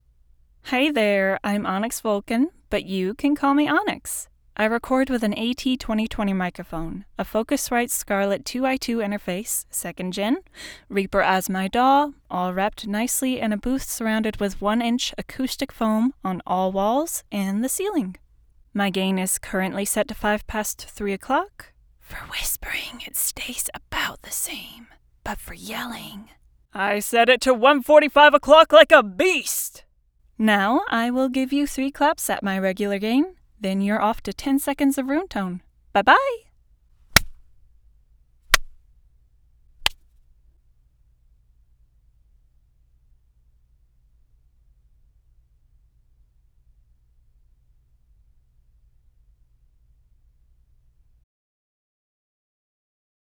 A Gem of a Voice with a Volcanic Flair